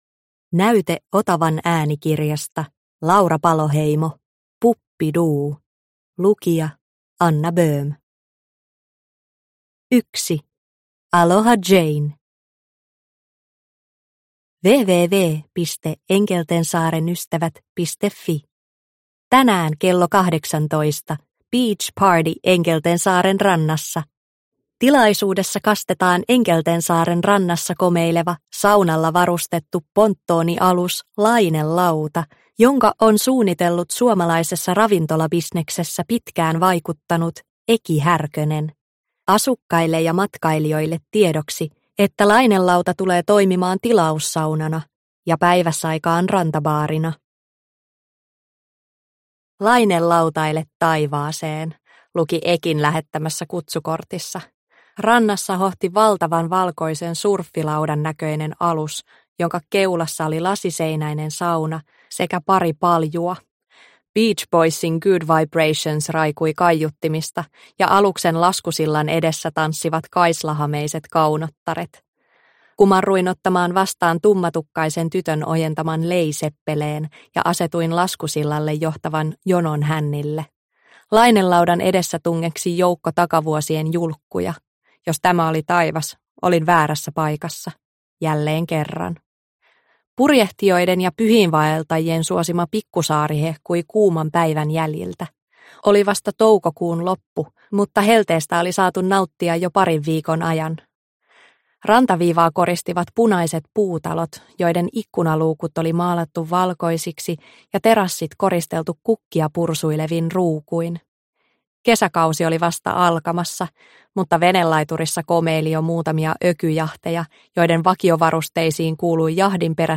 Puppiduu – Ljudbok – Laddas ner